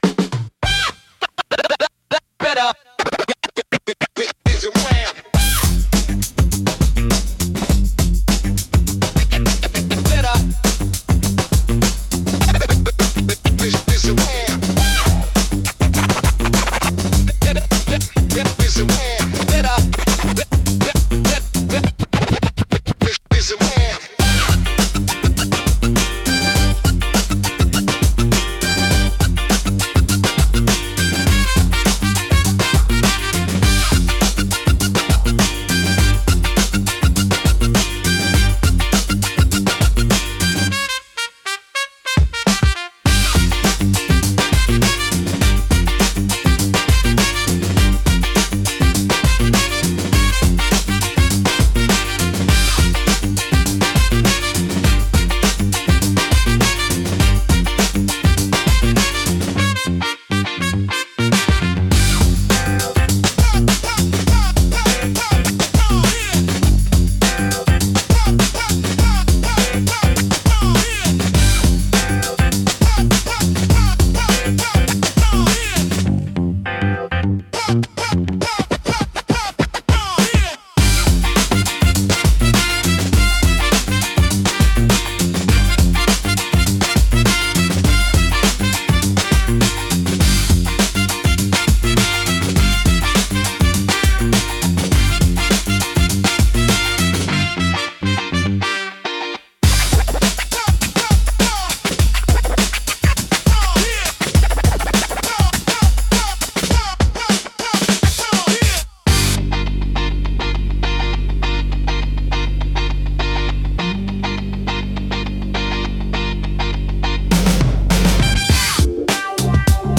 若々しく自由なエネルギーを感じさせ、都会的でポップな空間作りや動画配信の明るい雰囲気づくりに効果的です。